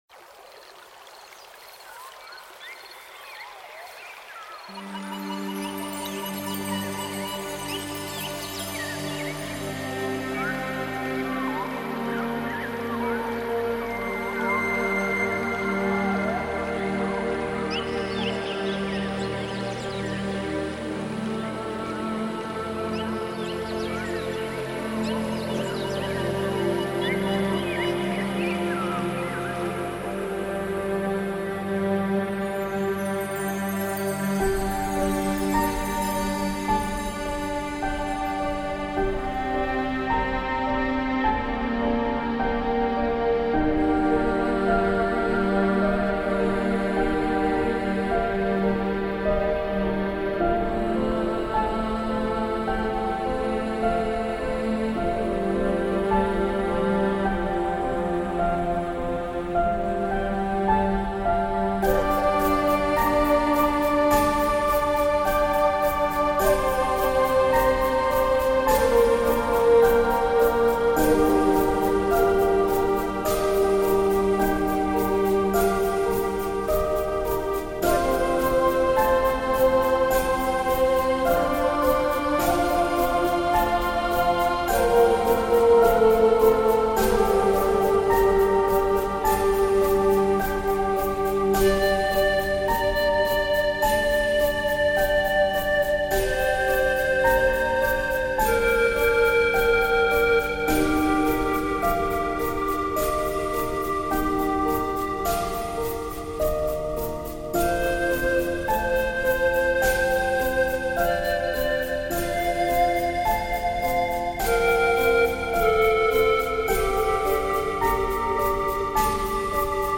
Zen, Relaxing, Enchantin, Ethnic Music